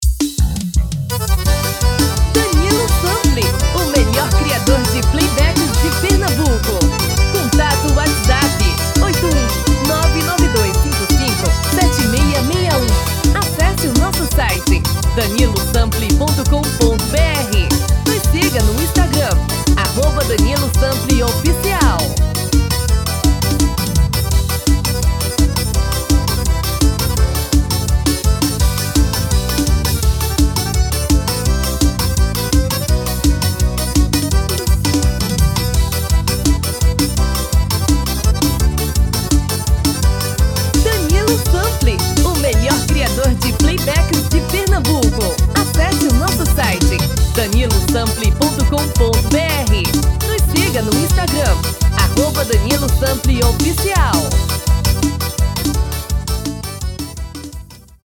DEMO 1: tom original / DEMO 2: tom feminino